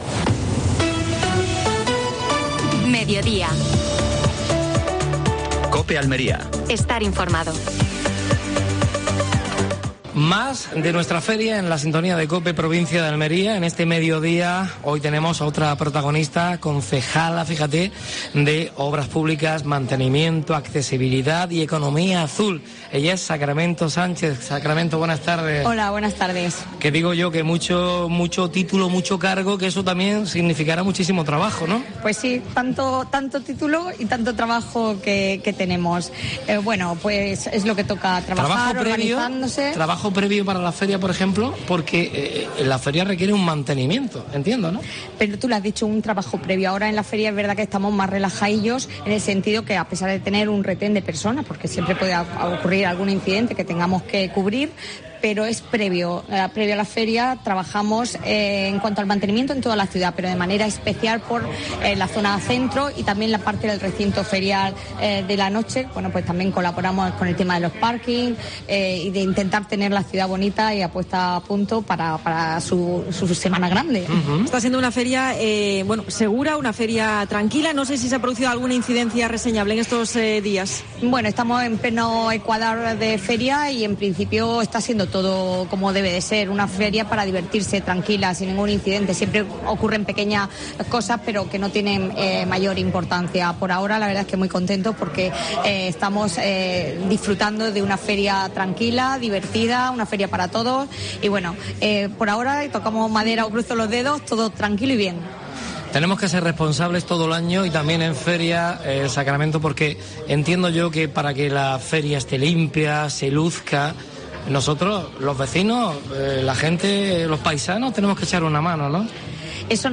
AUDIO: Programa especial de la Feria de Almería. Entrevista a Sacramento Sánchez (concejal del Ayuntamiento de Almería).